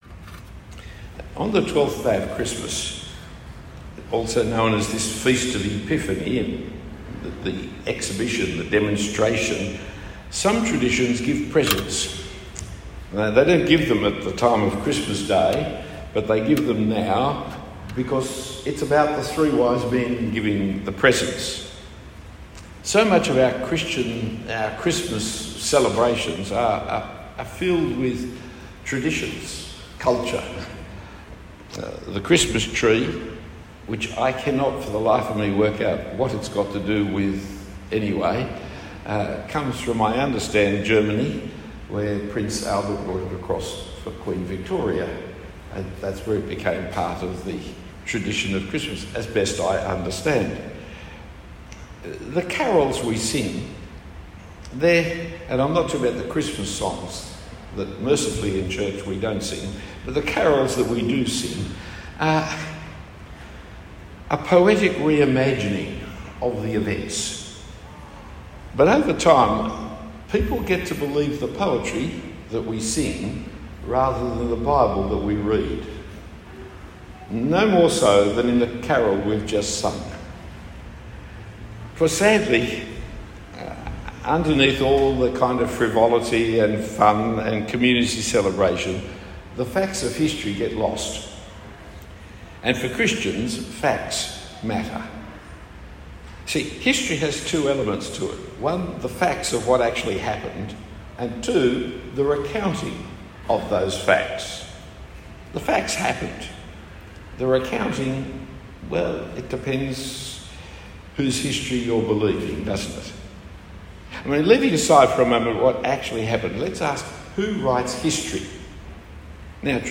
Jesus and the Nations Talk 4 of 4 given in the Christmas period at St Nicolas Coogee.